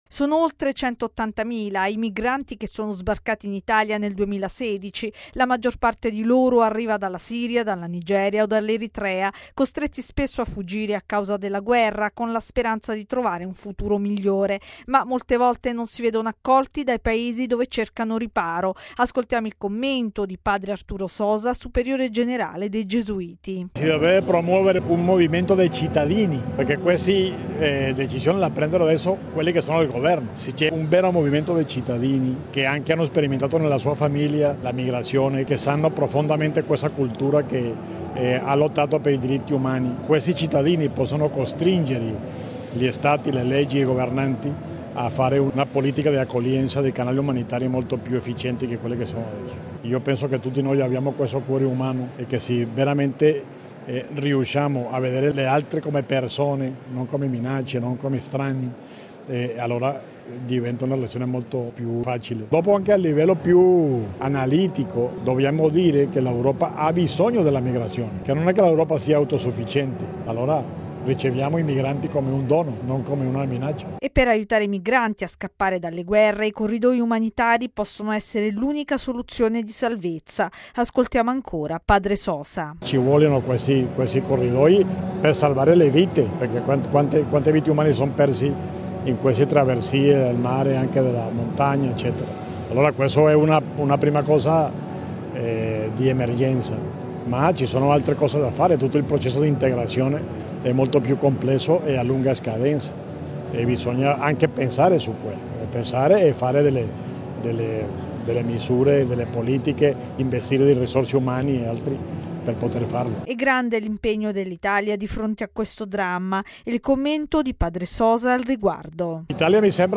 Accogliamo chi è costretto a fuggire senza avere paura, con il cuore aperto come i bambini: è l'invito lanciato da padre Arturo Sosa, superiore generale dei Gesuiti, in un incontro con i rifugiati e i volontari del Centro Astalli, svoltosi nella Chiesa del Gesù a Roma. L’iniziativa è stata organizzata in occasione della Giornata mondiale del migrante e del rifugiato.
Ascoltiamo il commento di padre Arturo Sosa, superiore generale dei Gesuiti: